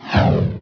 whosh_1.wav